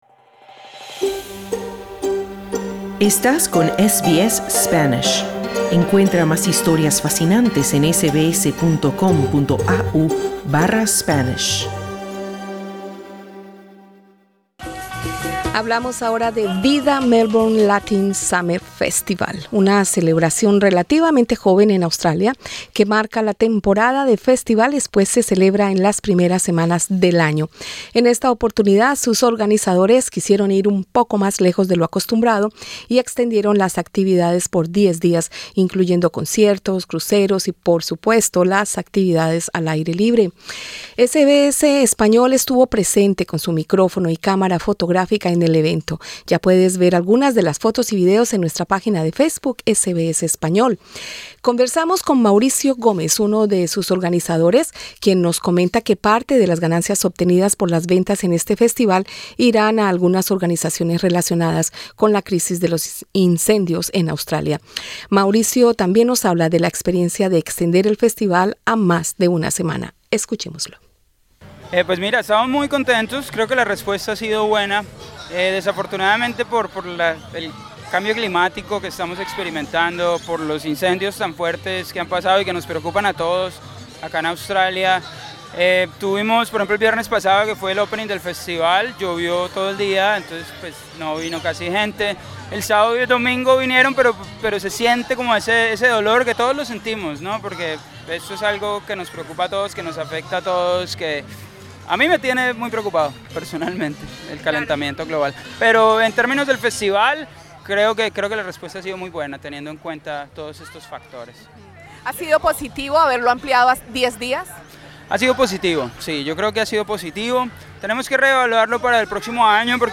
Asistentes al VIDA Melbourne Latin Summer Festival nos dan su opinión sobre la importancia de realizar con frecuencia festivales latinos en Australia.
SBS español estuvo presente con su micrófono y cámara fotográfica en el evento.